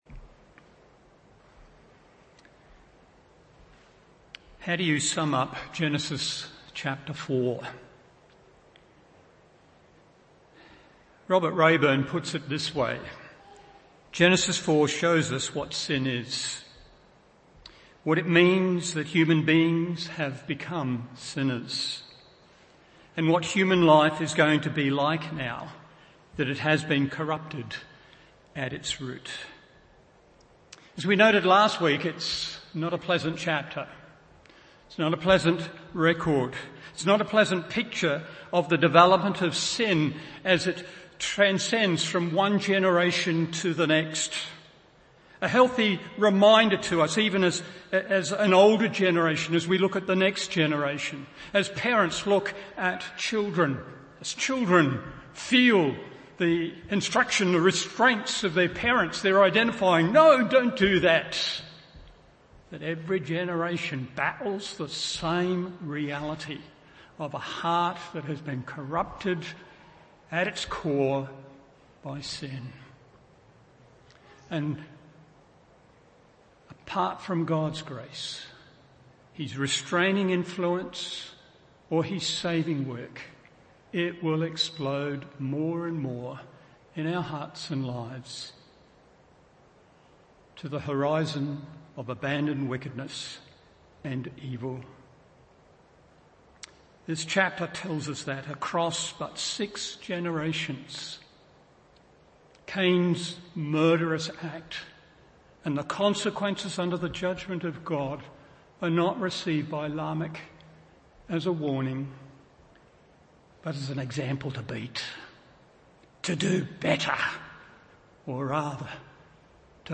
Morning Service Genesis 4:25-26 1.